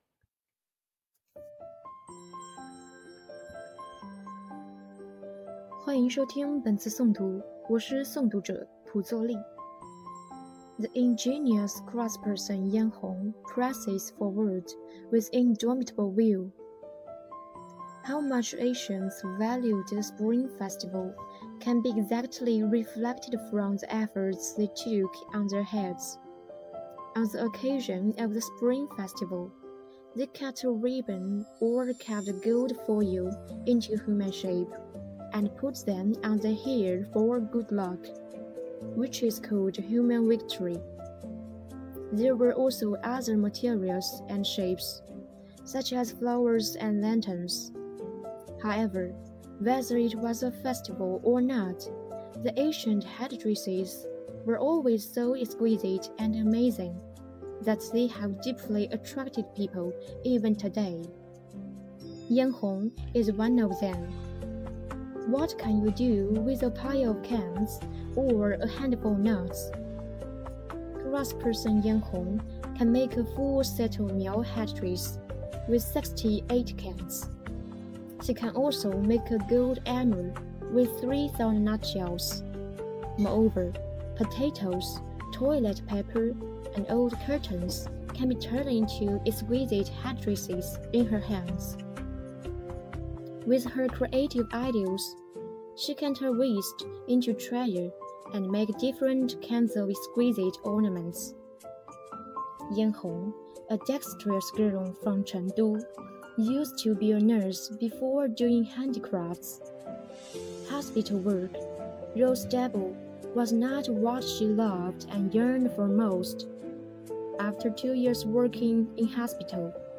Voice-over